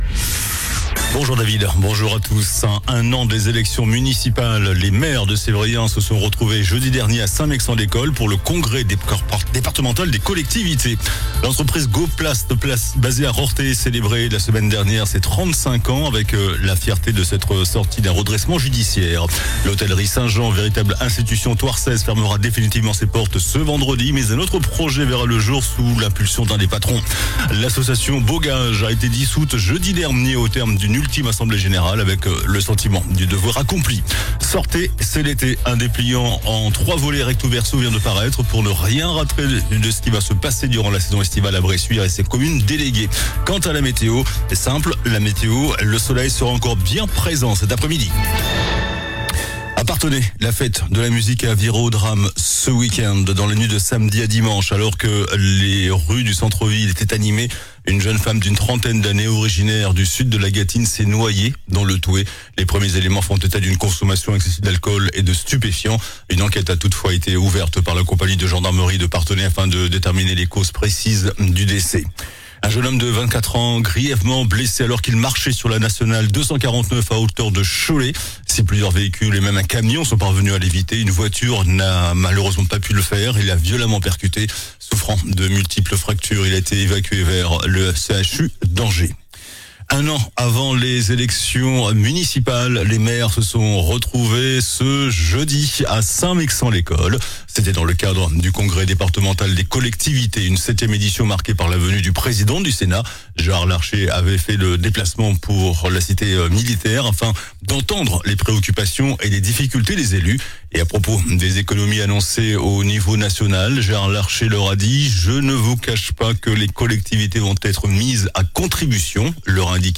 JOURNAL DU LUNDI 23 JUIN ( MIDI )